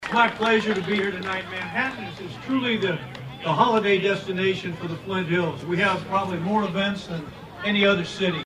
Mayor Wynn Butler says the parade, which doubles as a food drive for the Flint Hills Breadbasket was another huge success.